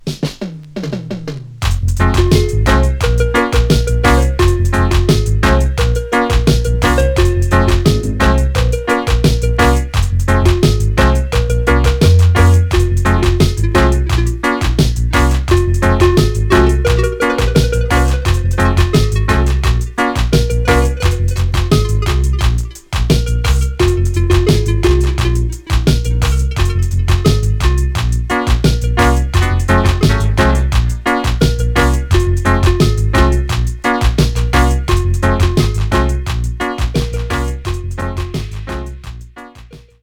Genre: Dancehall, Reggae